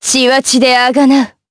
Lewsia_B-Vox_Skill1_jp.wav